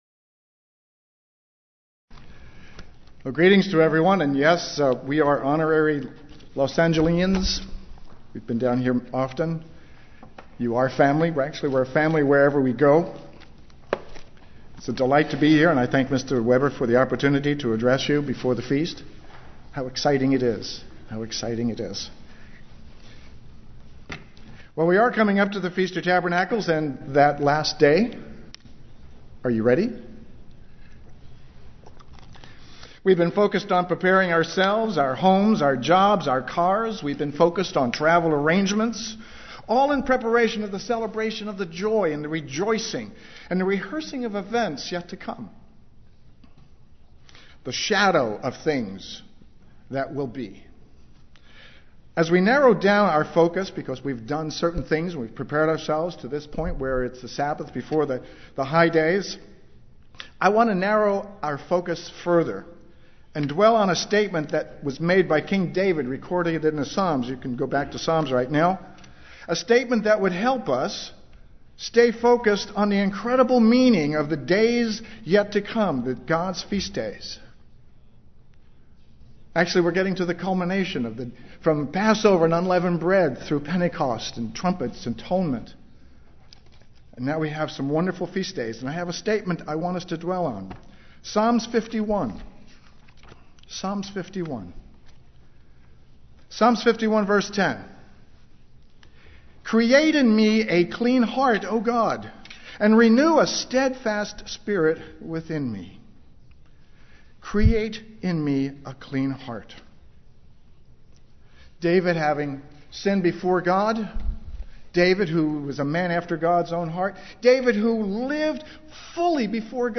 God wants truth, righteousness, and a clean heart within each of us. This message, given the Sabbath before the Feast of Tabernacles, focuses on preparing our hearts and minds as we go into the Holy Day season and beyond.
UCG Sermon Studying the bible?